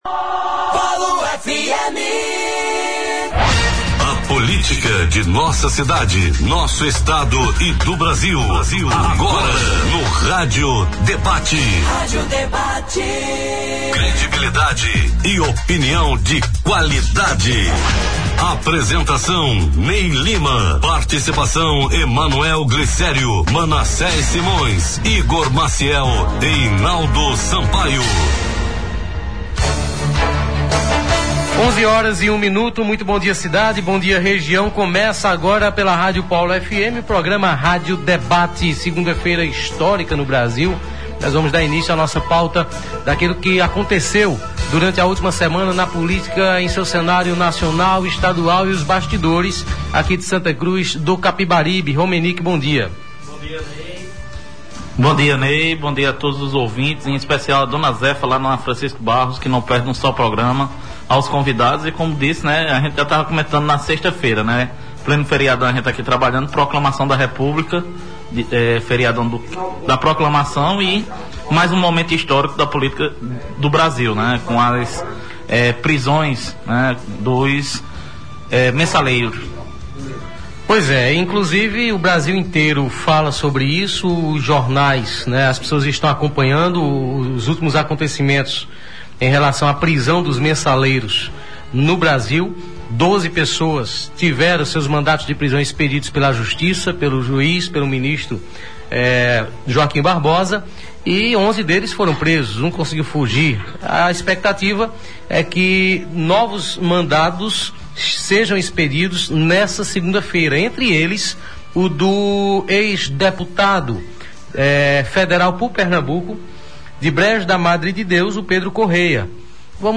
Participando também por telefone, o vereador Galego de Mourinha (PTB) confirmou o seu apoio ao nome de Ernesto para 2014 e disse não ver mais o ex-prefeito Toinho do Pará como taboquinha.